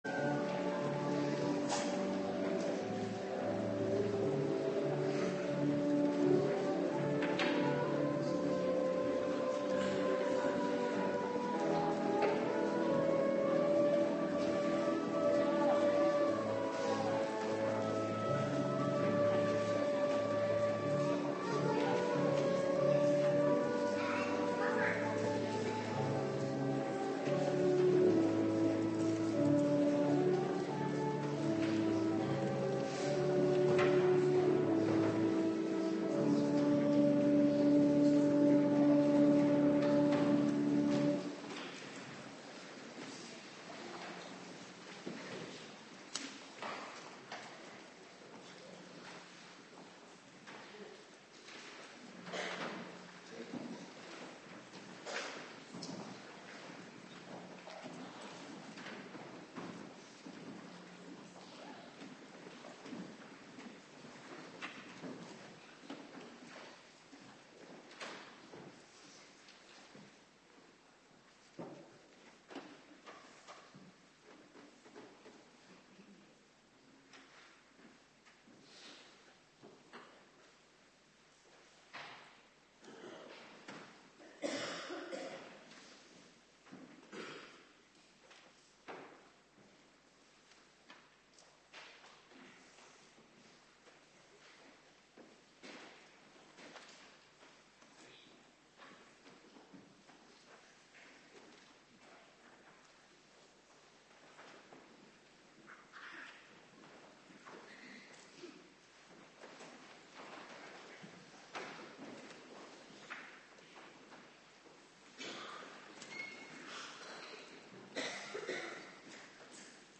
Morgendienst Dankdag
09:30 t/m 11:00 Locatie: Hervormde Gemeente Waarder Agenda